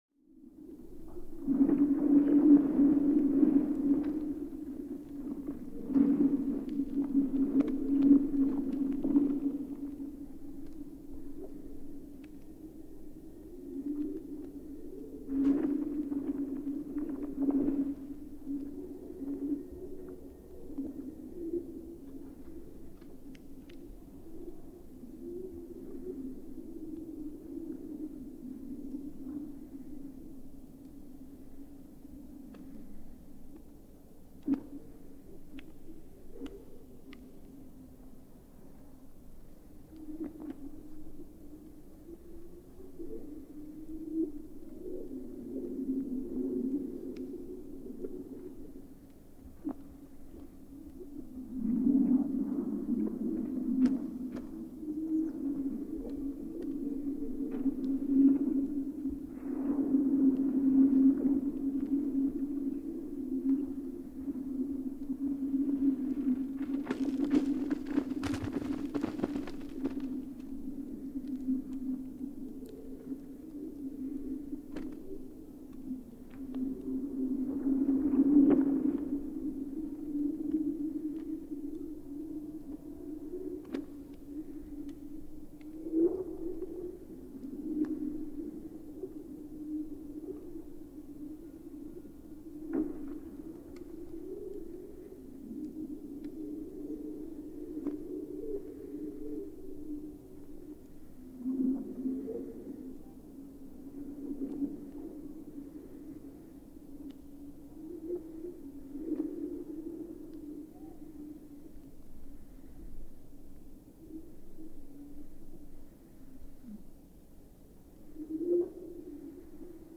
Recorded by National Park Service.
Listen to the rare, wintertime song of Yellowstone Lake as the ice reacts to changing temperatures.